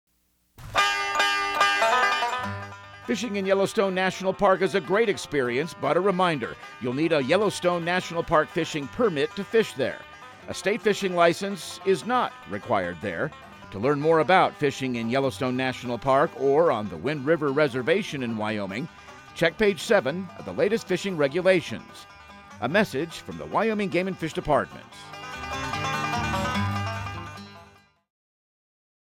Radio news | Week of July 14
OutdoorTip/PSA